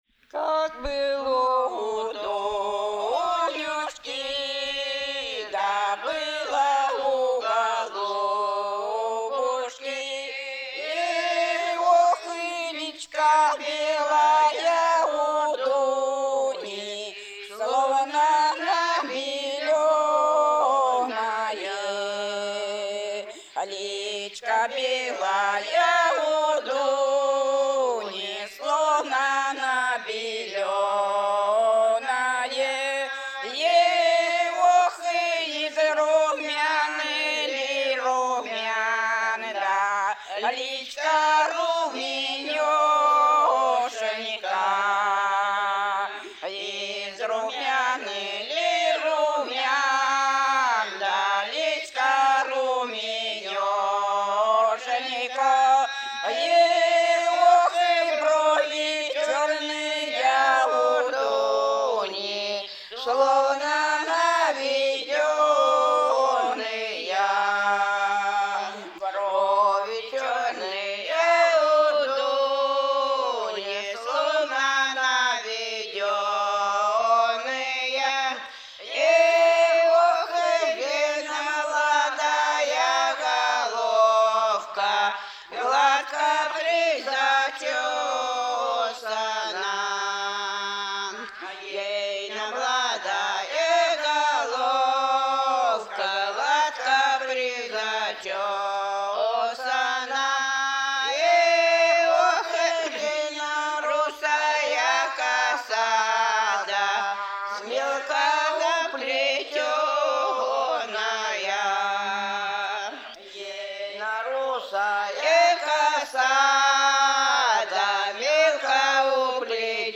Исполнитель: фольклорная группа с. Шуньга
Место записи: с. Шуньга, Медвежьегорский район, Республика Карелия.